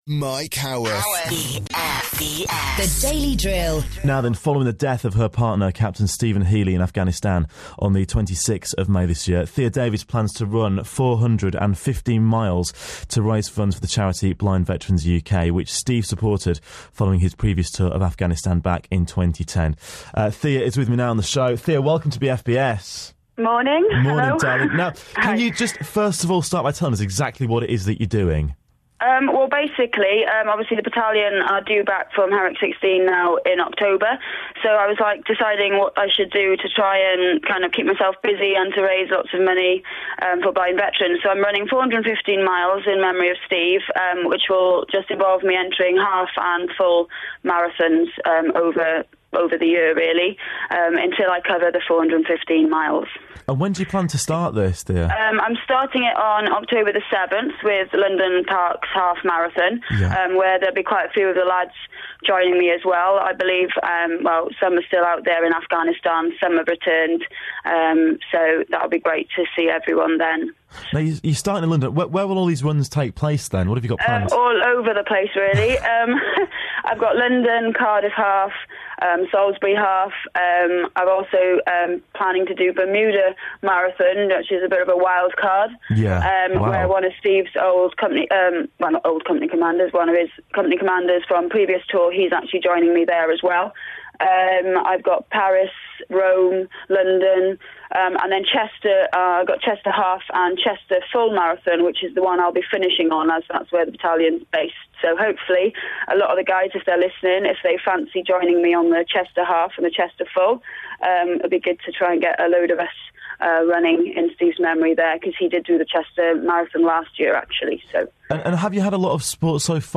415 Miles - BFBS Interview Part One